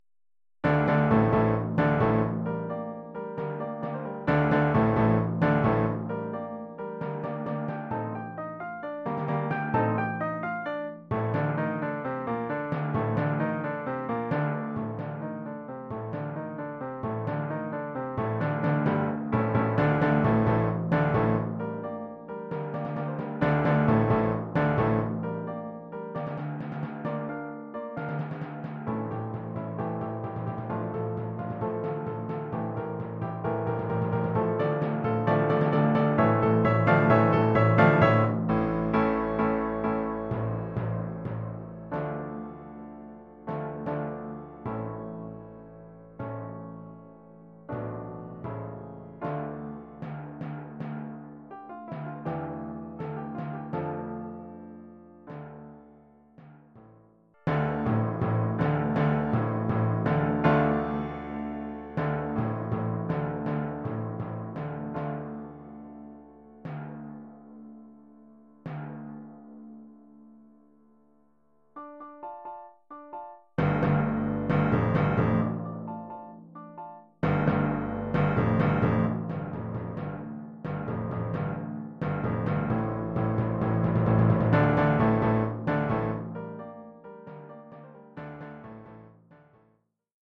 Oeuvre pour timbales et piano.